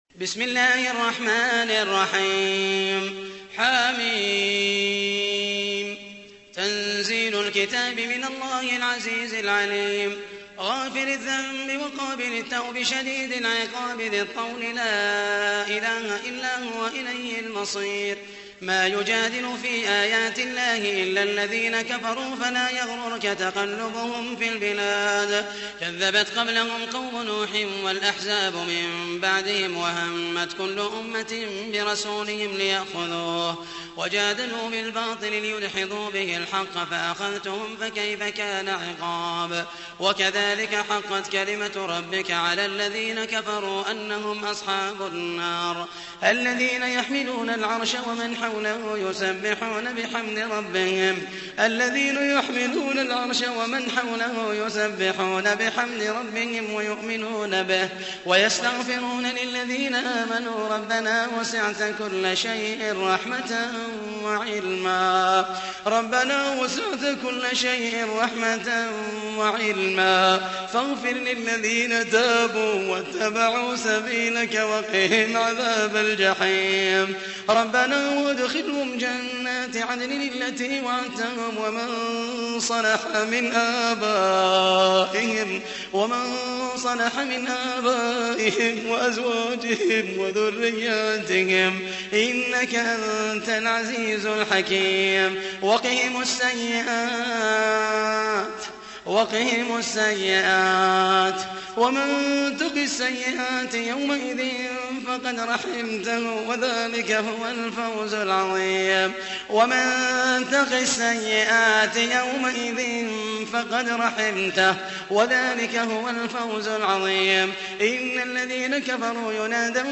تحميل : 40. سورة غافر / القارئ محمد المحيسني / القرآن الكريم / موقع يا حسين